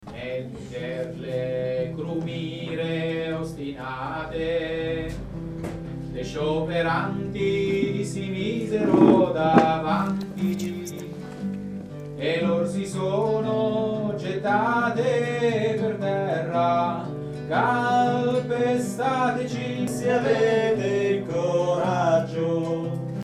Mondine_contro_tenori.mp3